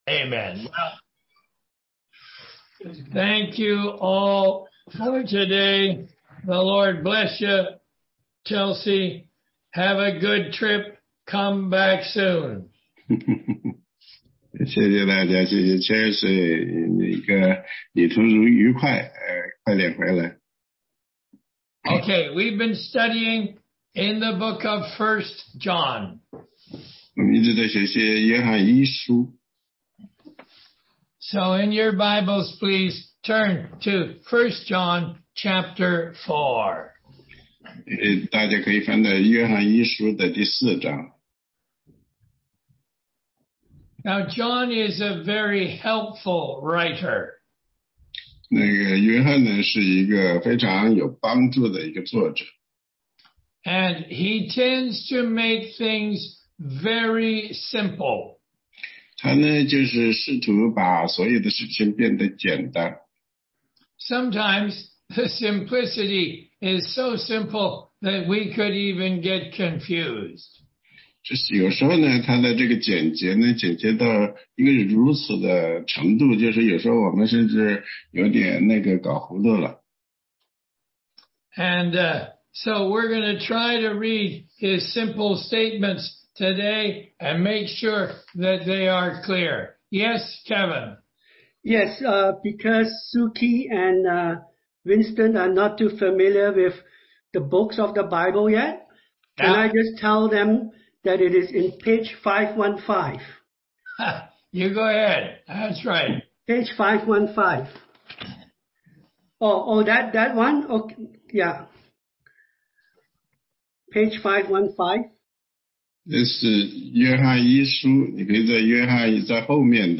16街讲道录音 - 约一4章16节-5章5节
答疑课程